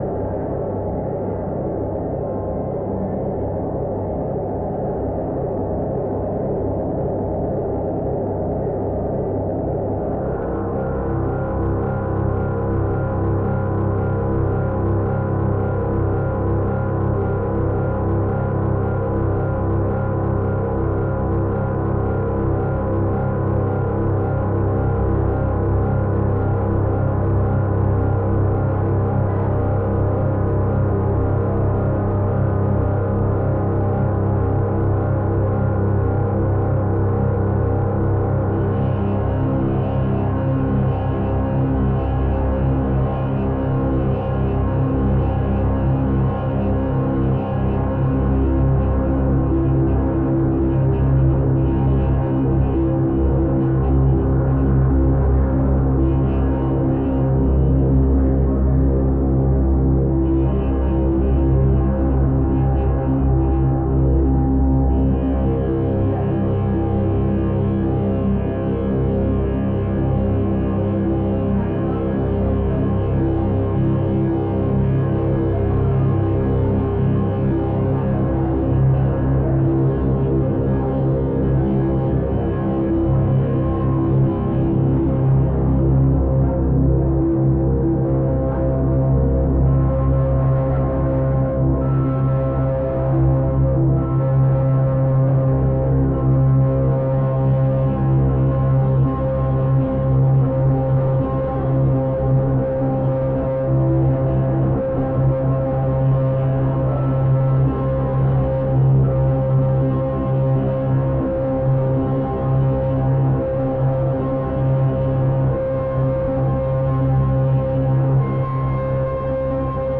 ambient drones and etheral soundscapes
Live at The Ashmolean
Some live tracks can be found below (the quality is not as good as what was played on the night!).
fairlight-live.wav